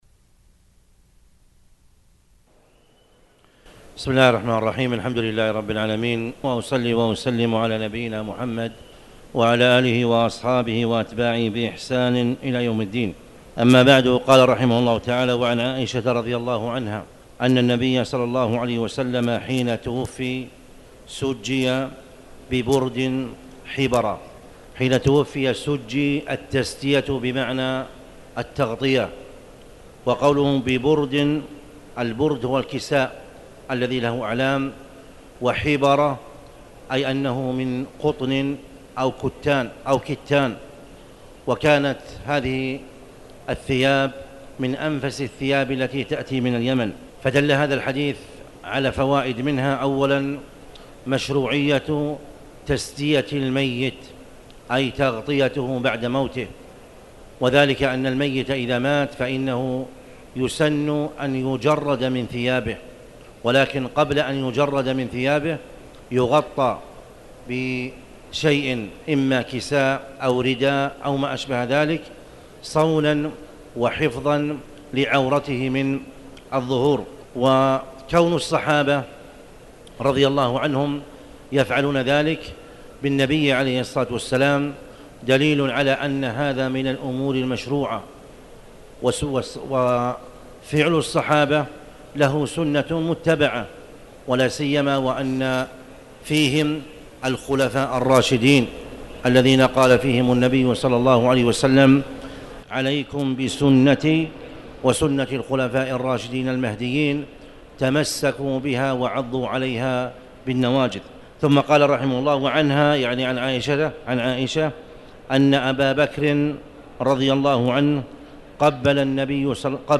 تاريخ النشر ٢٨ جمادى الأولى ١٤٣٩ هـ المكان: المسجد الحرام الشيخ